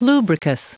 Prononciation du mot lubricous en anglais (fichier audio)
Prononciation du mot : lubricous